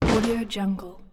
دانلود افکت صدای کوبیدن درب به هم 4
دانلود افکت صدای کوبیدن درب چوبی به هم 4
Sample rate 16-Bit Stereo, 44.1 kHz
Looped No